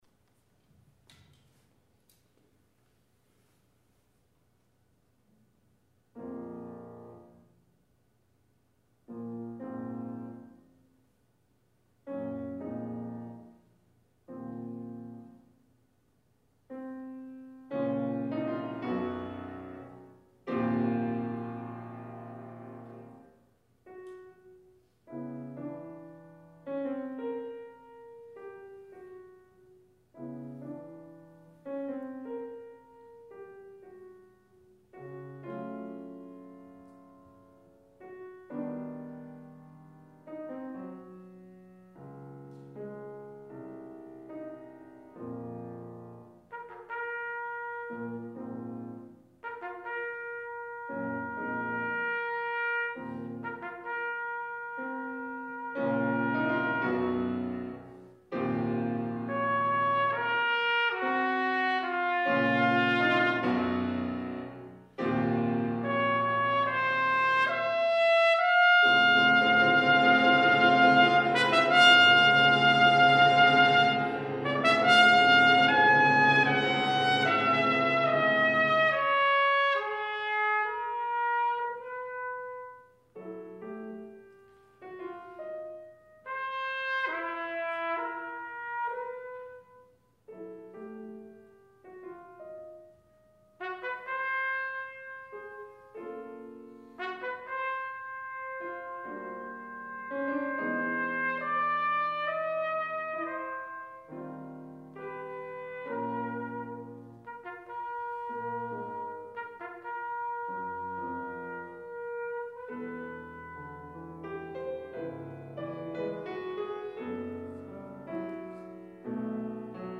Two more ideas are presented, with the movement’s eventual form set out in the neatly symmetrical arrangement of A-B-C-A-C-B-A. The second movement has a quirky, whimsical air to it, somewhat like a comical march but with a pronounced undercurrent of tension.
(The recording below is from a recital I gave at UW-Platteville in 2017.  I used a Bach Artisan Bb)
There are no mutes needed.
Movement 1 (With Strength)
Movement 3 (Mourning Music)
Bb trumpet